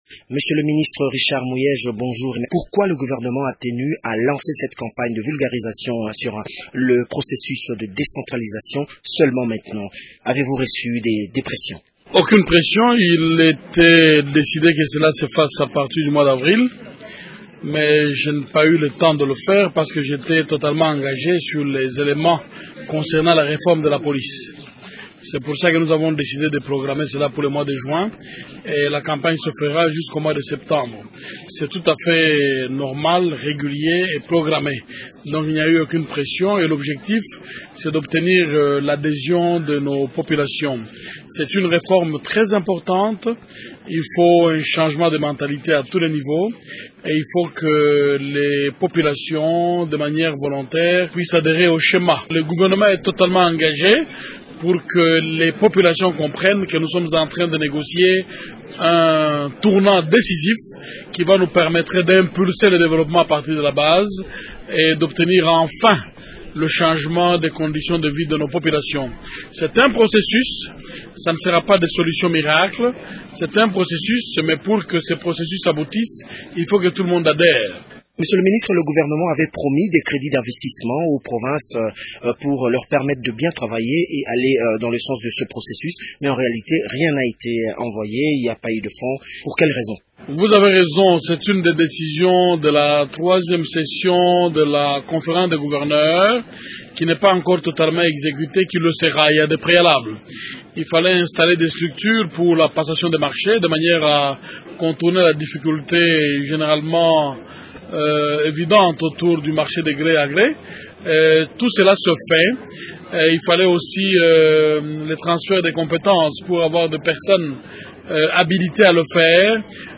Le ministre de l’Intérieur Richard Muyej est l’Invité de Radio Okapi ce vendredi.